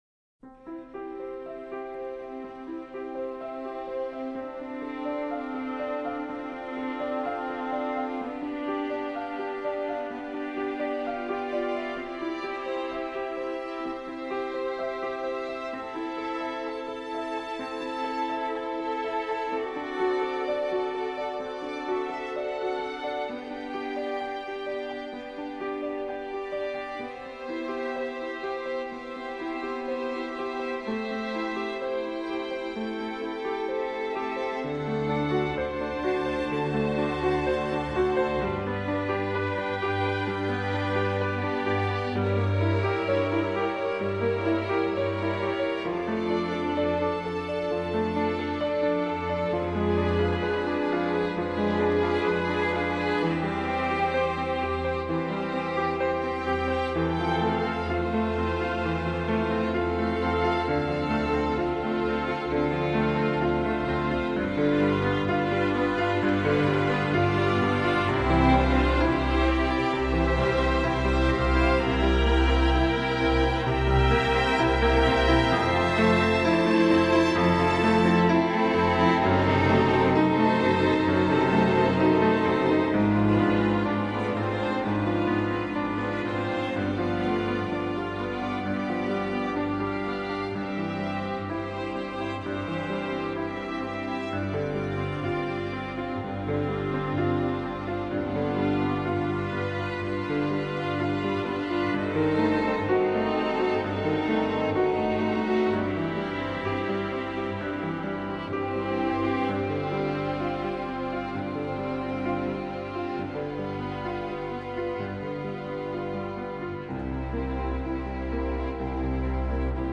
Voicing: String Orchestra W